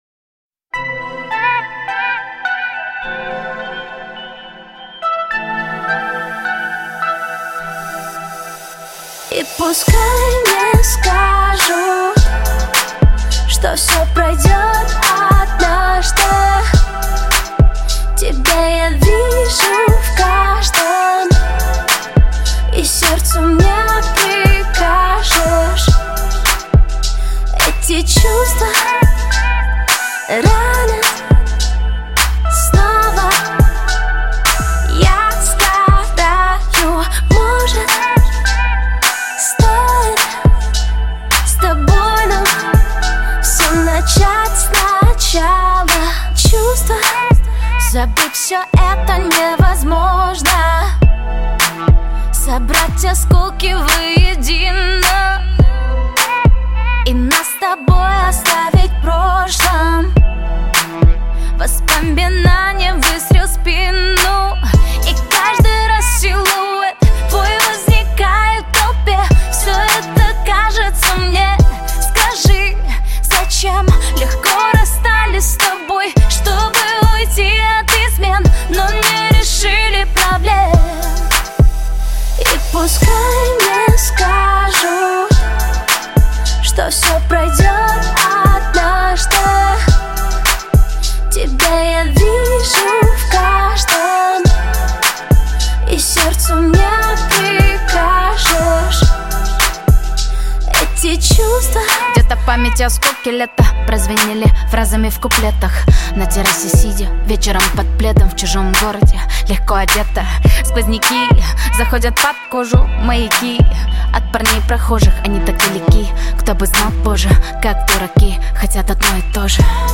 Поп-музыка
Жанр: Поп-музыка / В машину